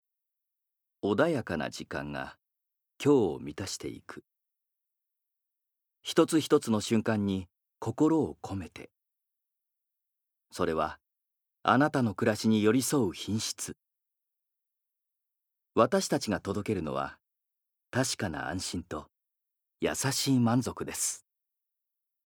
Voice Sample
ナレーション３